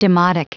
Prononciation du mot demotic en anglais (fichier audio)
Prononciation du mot : demotic